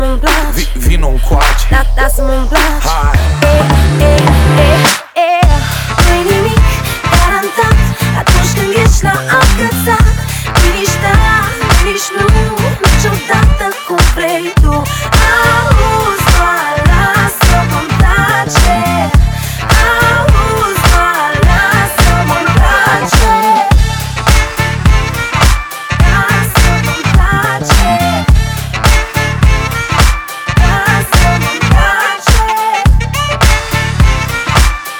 # Adult Contemporary